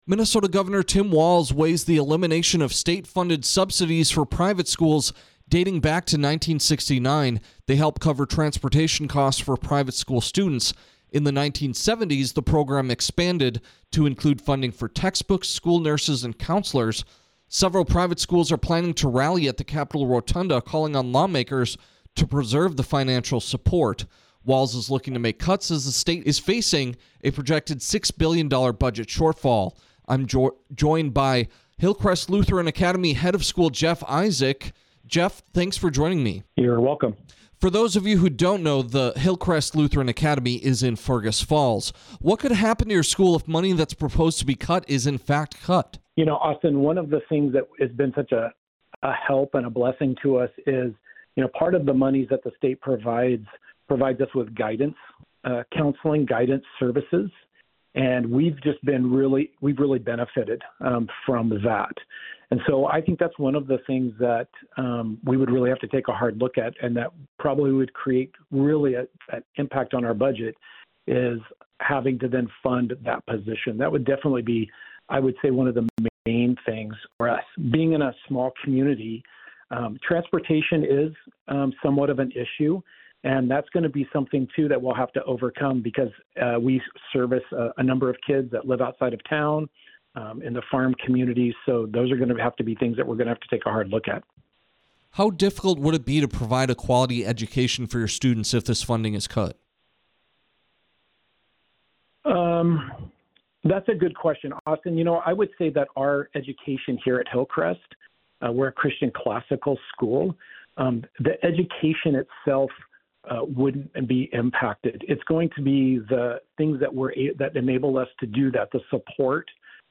hillcrest-lutheran-interview.mp3